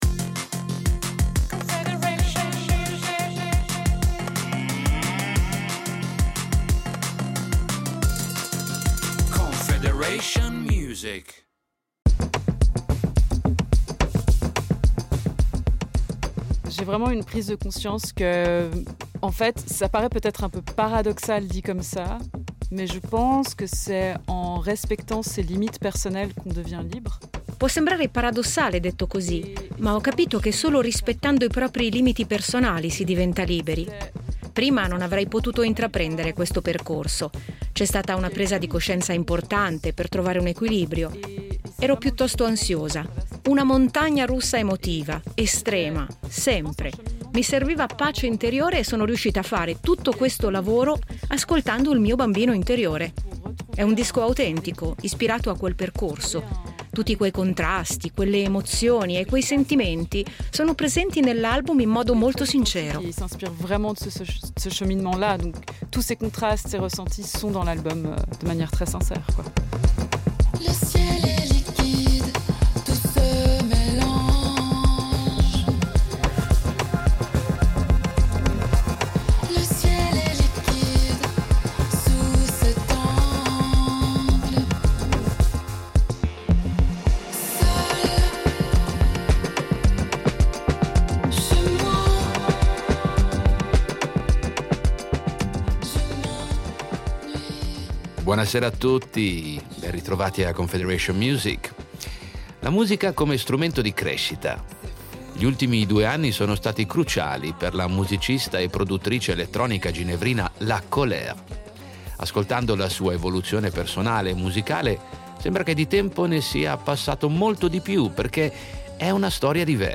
Musica elettronica